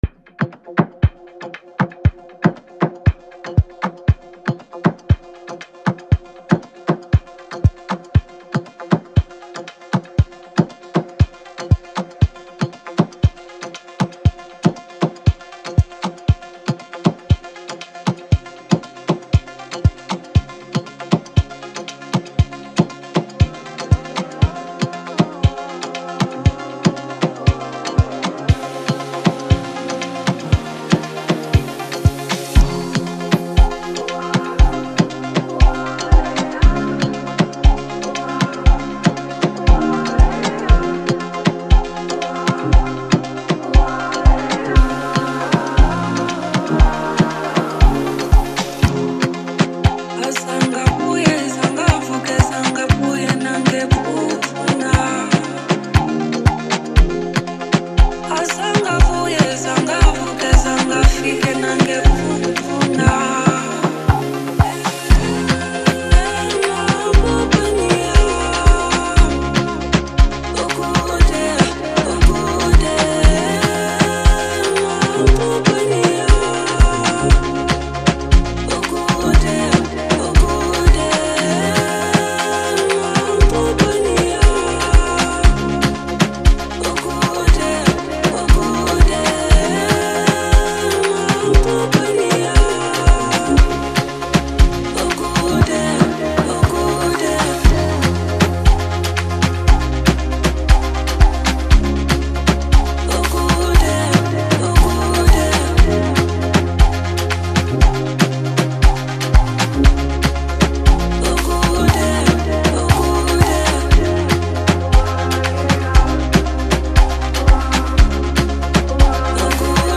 South African singer